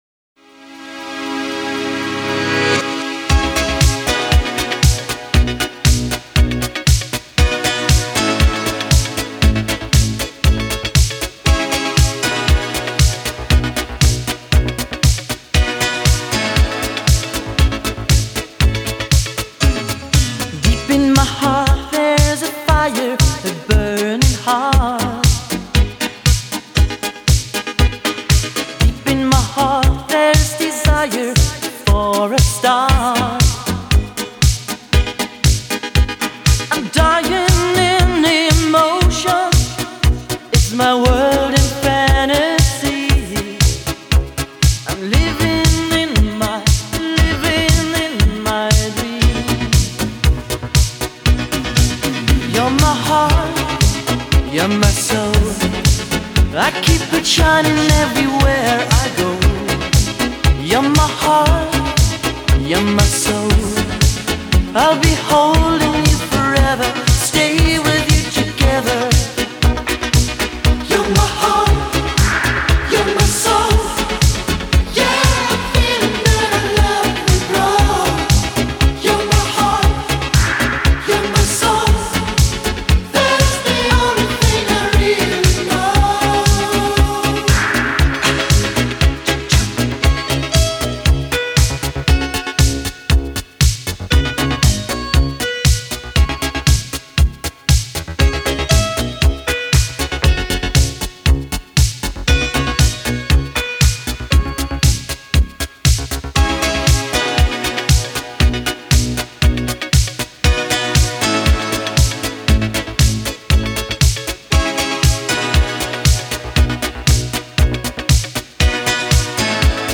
Ретро музыка
музыка 80-х